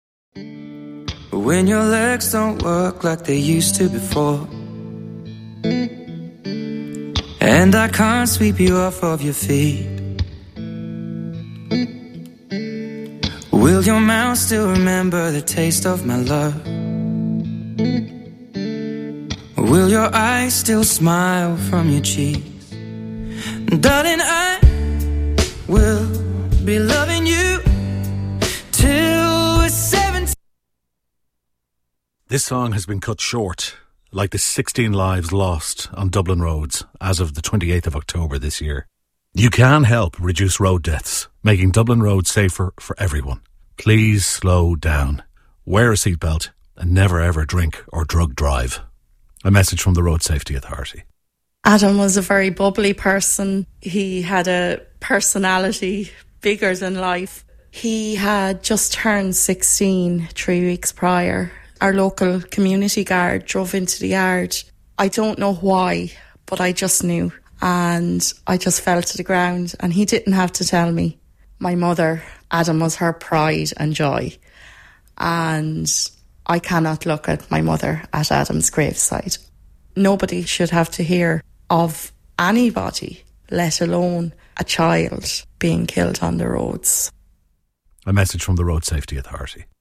Inserted into regular programming, the campaign used well-known songs that were abruptly cut off mid-track.
Each interruption was followed immediately by a short advertisement reminding listeners that seemingly minor behaviours like checking a phone, speeding or driving under the influence-can have fatal consequences.